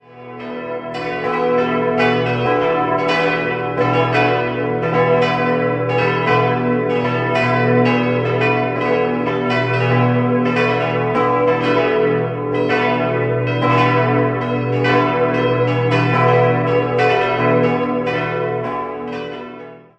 5-stimmiges erweitertes Westminster-Geläute: h°-e'-fis'-gis'-h' Alle Glocken wurden von Karl Czudnochowsky in Erding gegossen: Nr. 1 im Jahr 1955 (von der Pfarrei 1958 erworben), Nr. 5 im Jahr 1952, Nr. 2 und 4 im Jahr 1949 und Nr. 3 bereits 1948.